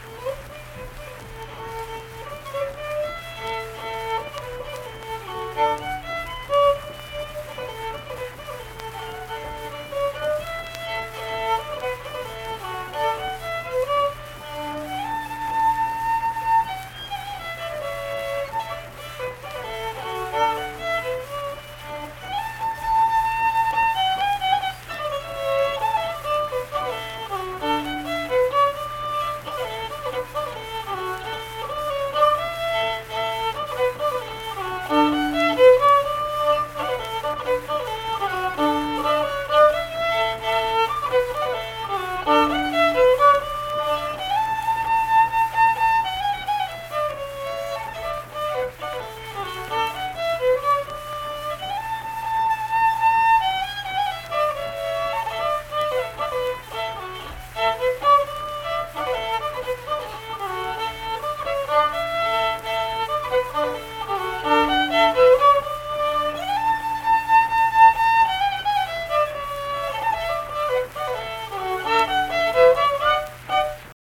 Unaccompanied fiddle music performance
Verse-refrain 3(2).
Instrumental Music
Fiddle
Harrison County (W. Va.)